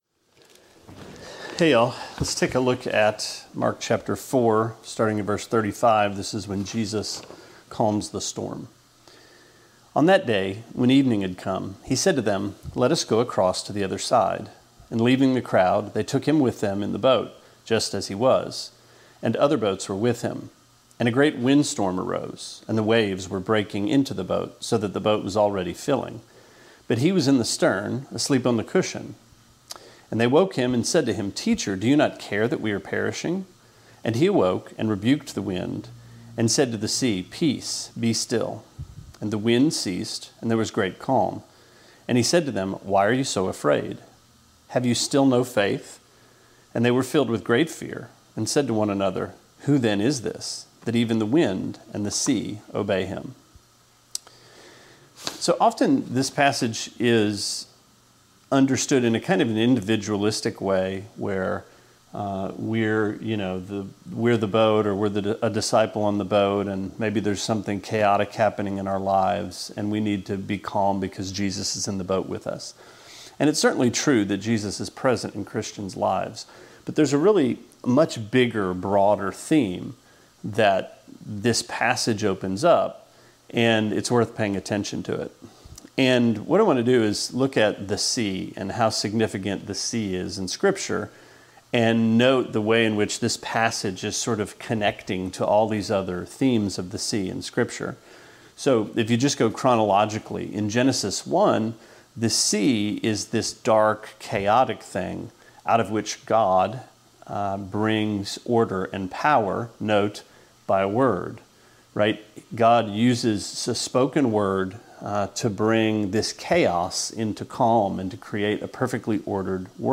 Sermonette 7/1: Mark 4:35-41: Shipwreck!